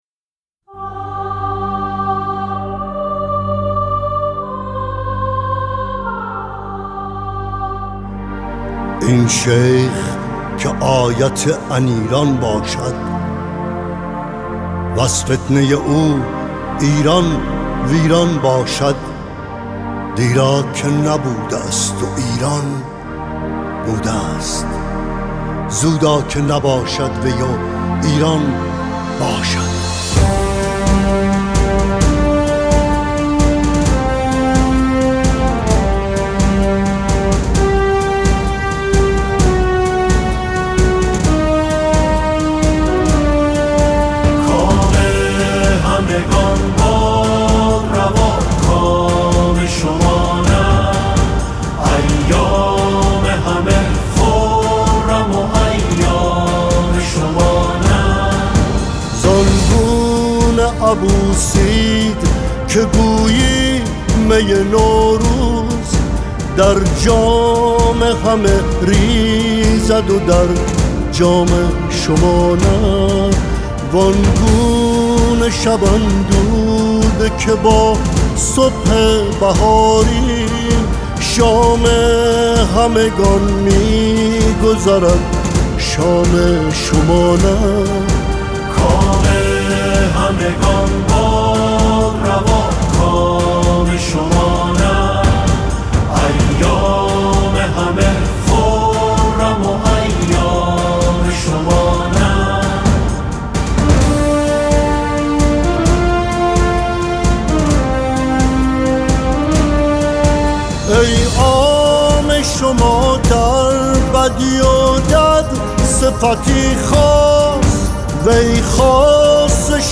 چه موسیقی حماسی و زیبایی